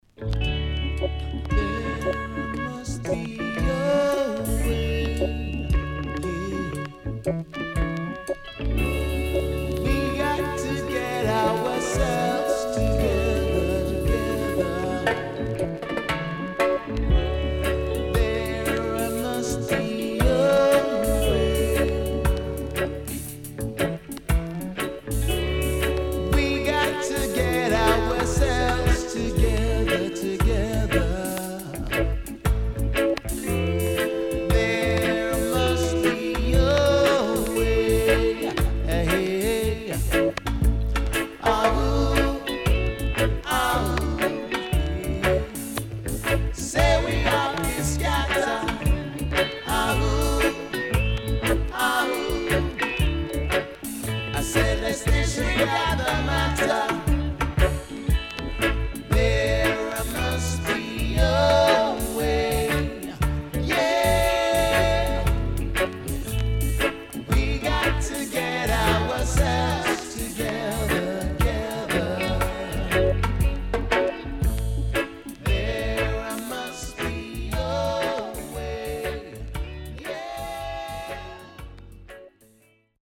Great UK Roots Album